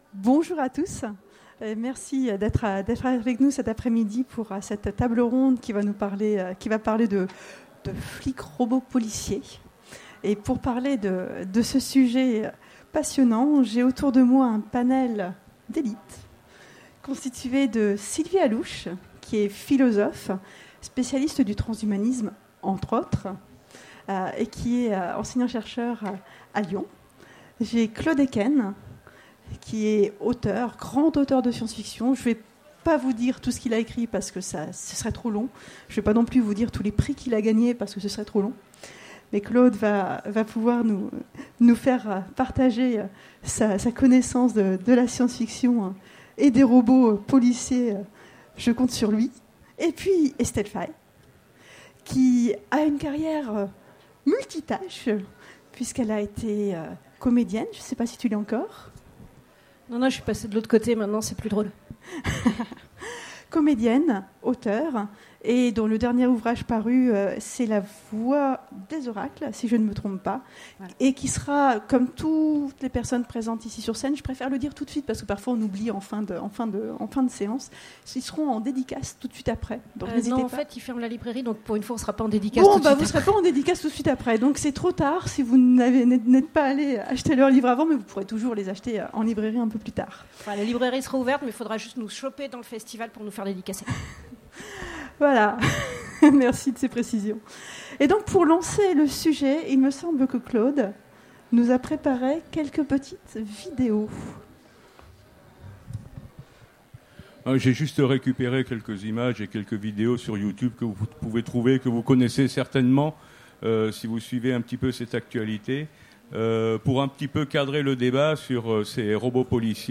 Utopiales 2016 : Conférence Le flic robot : de Robocop à la réalité